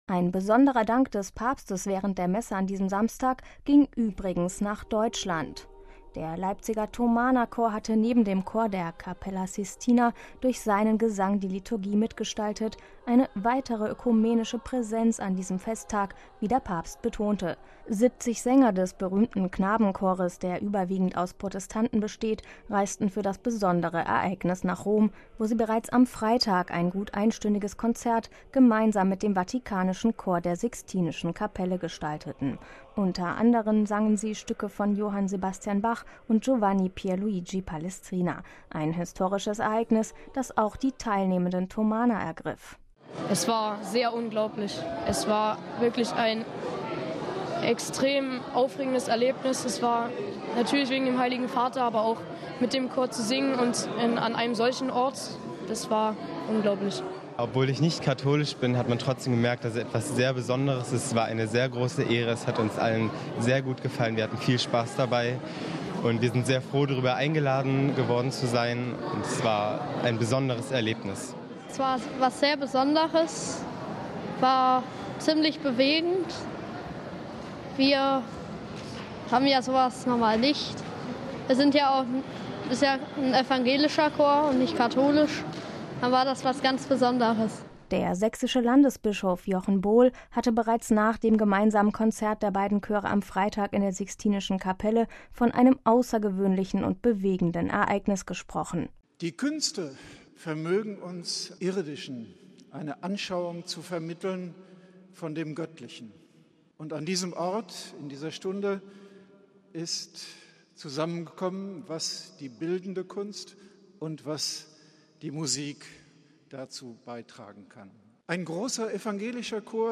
Der sächsische Landesbischof Jochen Bohl hatte bereits nach dem gemeinsamen Konzert der beiden Chöre am Freitag in der sixtinischen Kapelle von einem außergewöhnlichen und bewegenden Ereignis gesprochen: